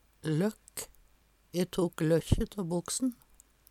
løkk - Numedalsmål (en-US)